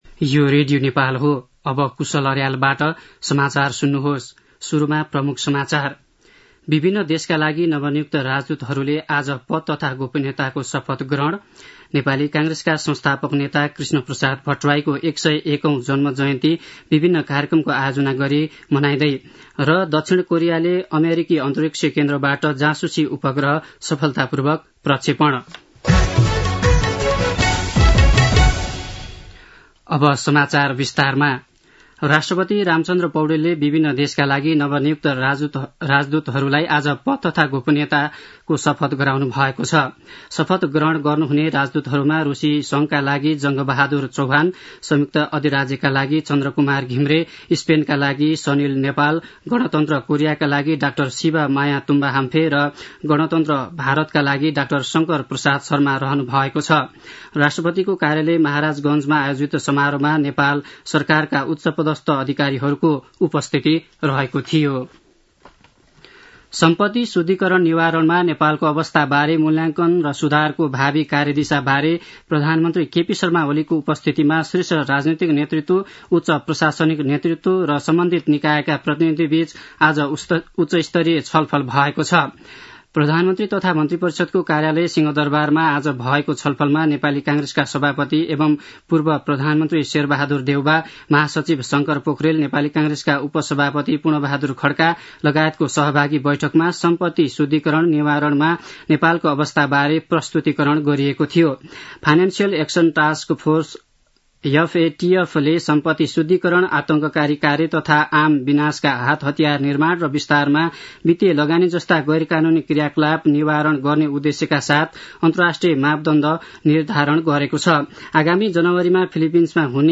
दिउँसो ३ बजेको नेपाली समाचार : ९ पुष , २०८१
3-pm-nepali-news-1-13.mp3